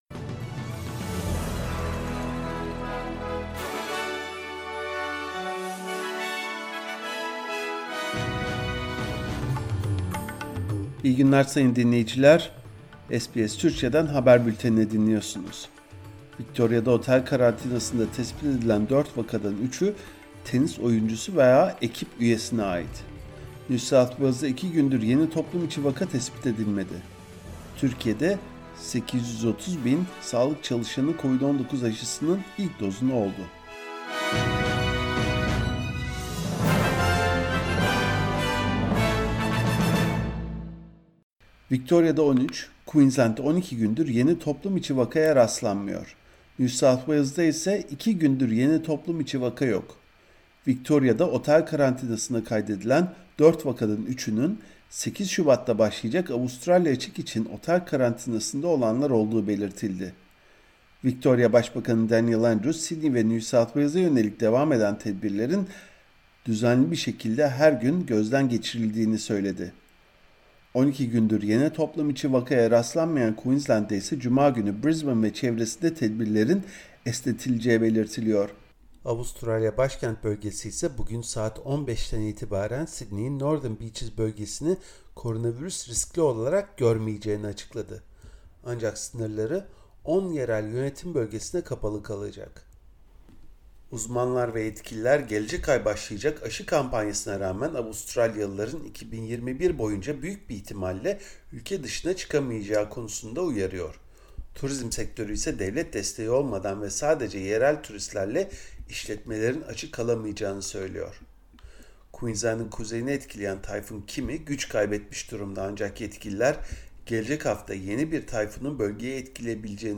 SBS Türkçe Haberler 19 Ocak